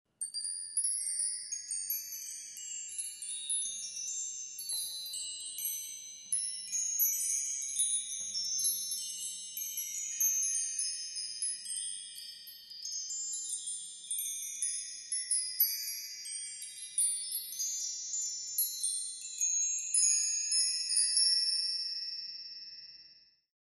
Звуки волшебной палочки
Шепот волшебной пудры, рассыпающейся после магии